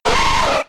Cri de Nidoran♂ K.O. dans Pokémon X et Y.